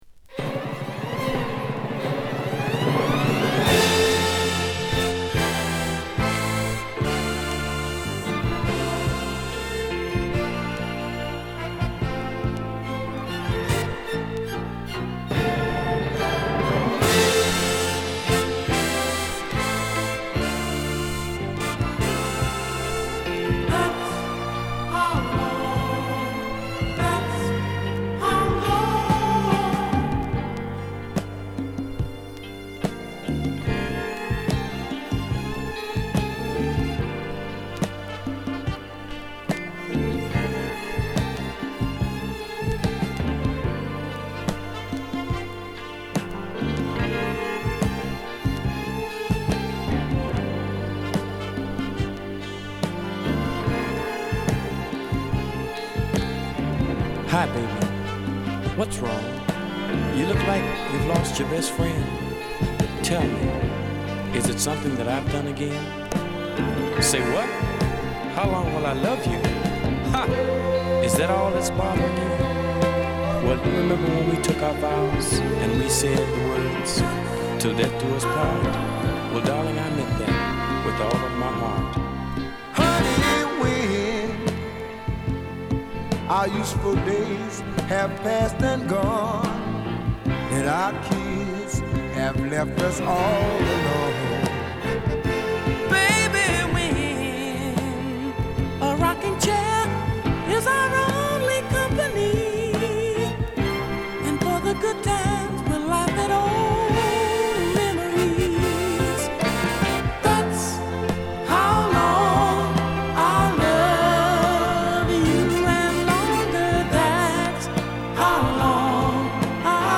アルバム通して甘いスウィート〜メロウチューンを満載